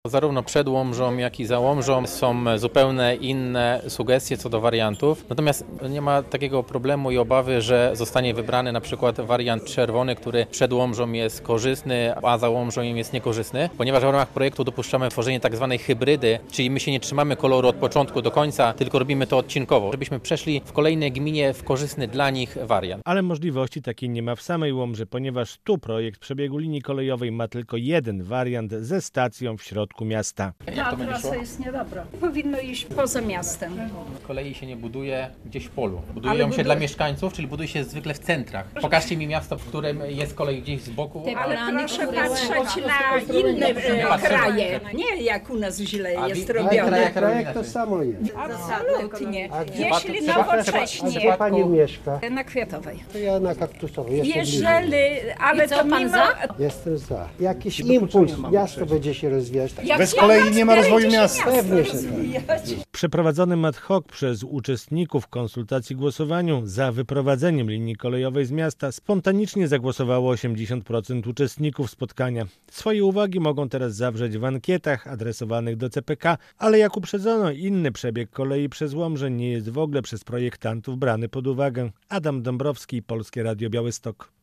W Łomży odbyły się konsultacje społeczne ws. przebiegu linii kolejowej 29 - relacja